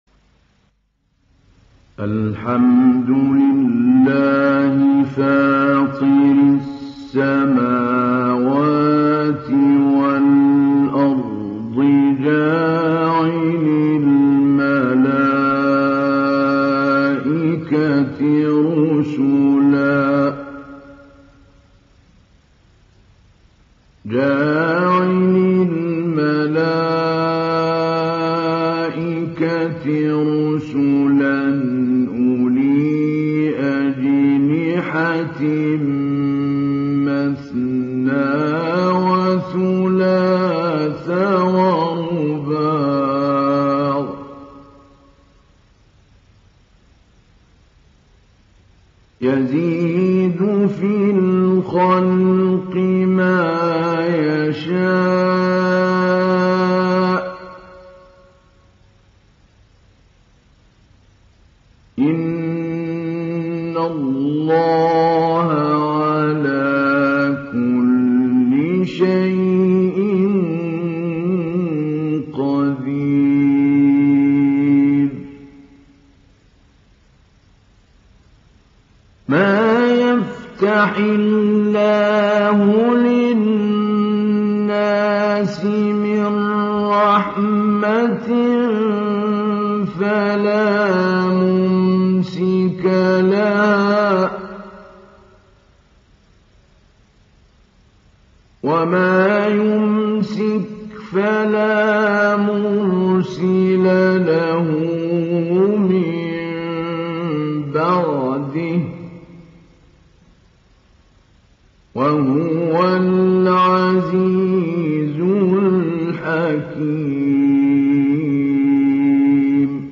ডাউনলোড সূরা ফাতের Mahmoud Ali Albanna Mujawwad